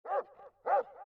dog1.mp3